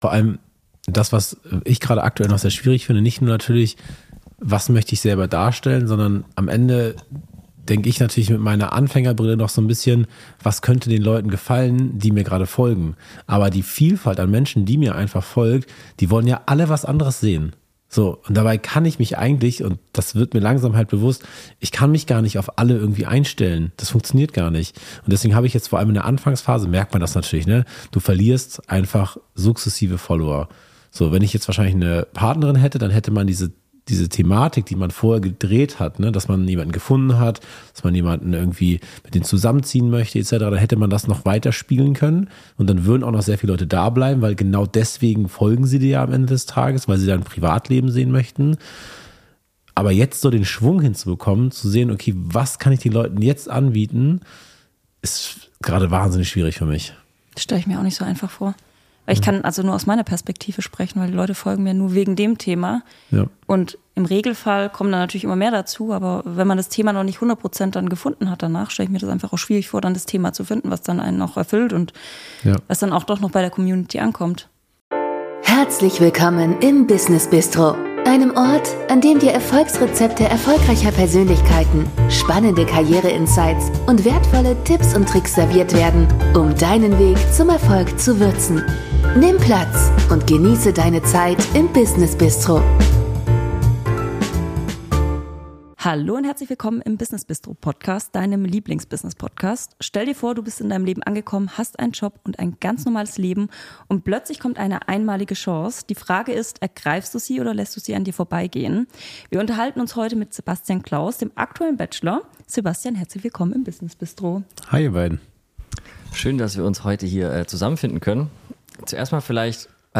Mit keinem anderen Satz wird unser heutiger Interview-Partner öfters in Verbindung gebracht.